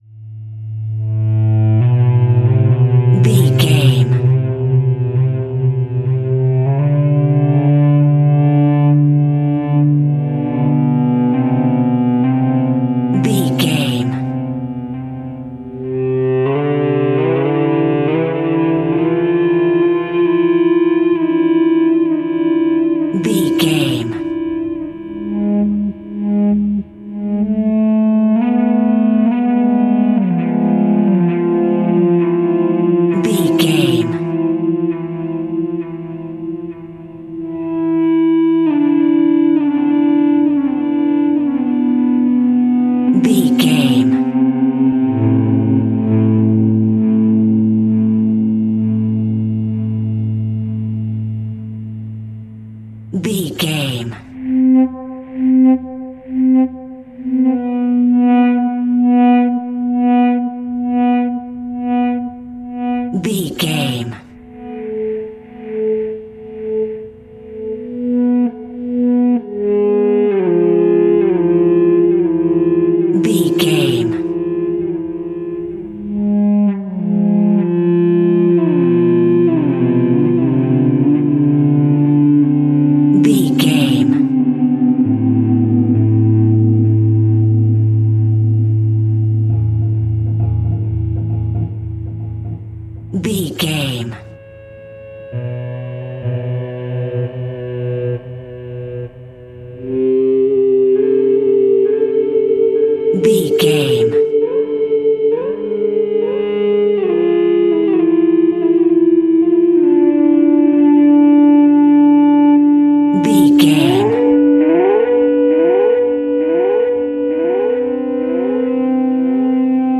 Thriller
Aeolian/Minor
Slow
scary
ominous
dark
haunting
eerie
strings
electric guitar
synth
pads